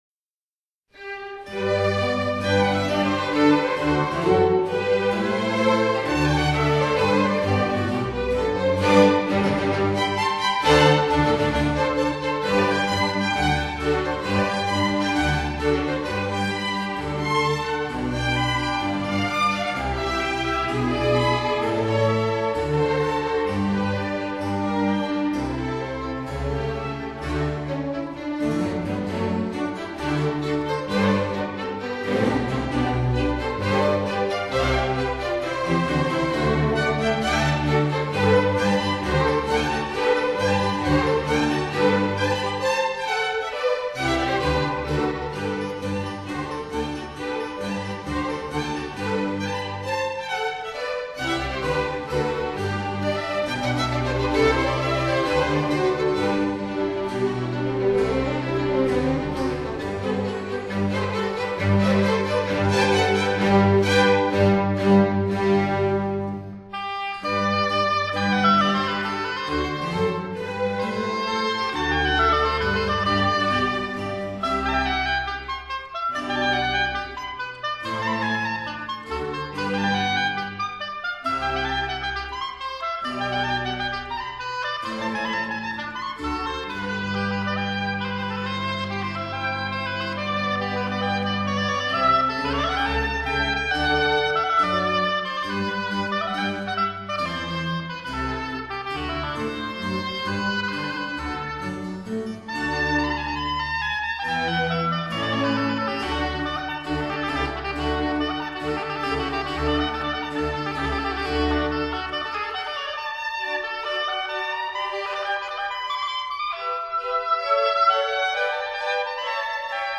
oboe